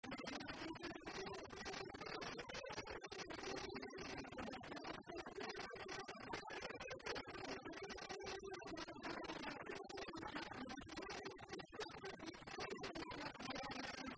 Rondes à baisers et à mariages fictifs
Pièce musicale inédite